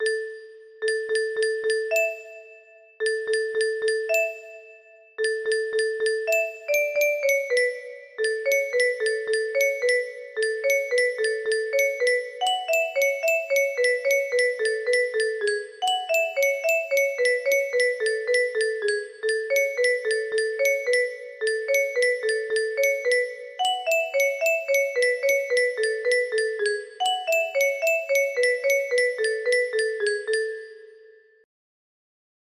Middle music box melody